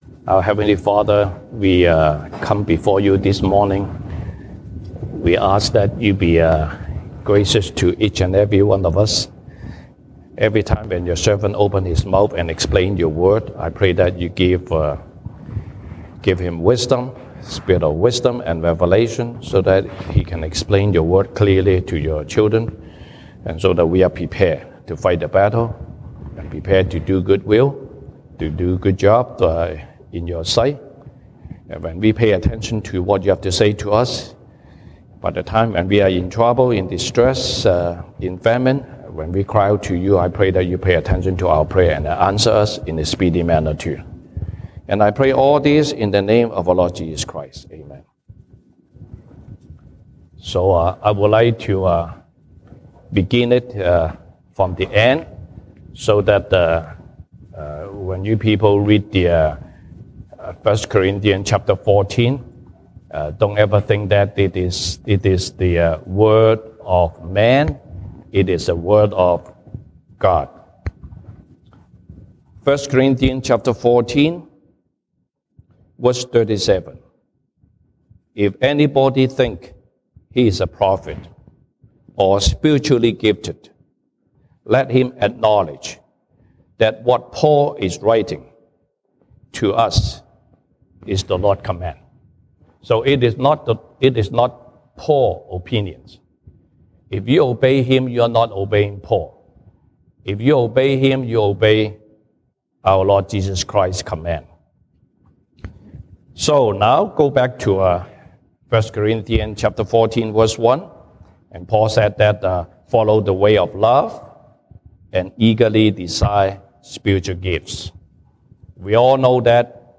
西堂證道 (英語) Sunday Service English: An effective ministry